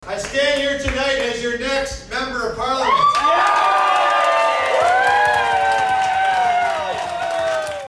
Ryan Williams made the statement very early Tuesday morning.